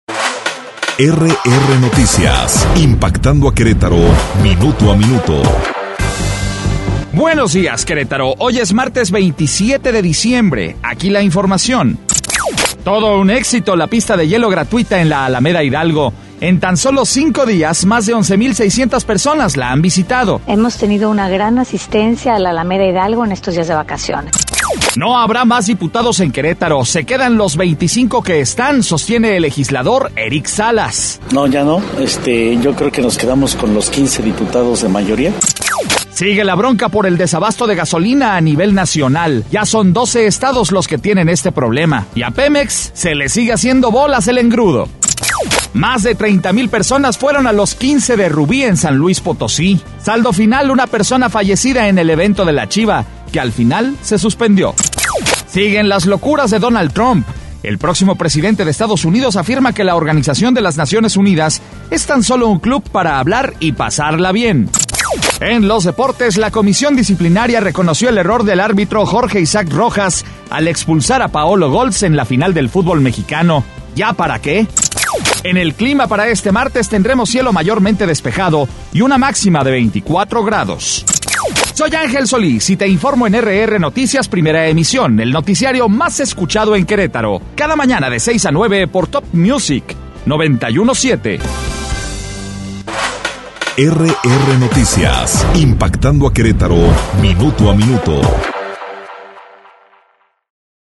Resumen Informativo 27 de diciembre - RR Noticias